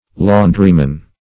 Search Result for " laundryman" : Wordnet 3.0 NOUN (1) 1. operates industrial washing machine ; [syn: washerman , laundryman ] The Collaborative International Dictionary of English v.0.48: Laundryman \Laun"dry*man\, n.; pl.
laundryman.mp3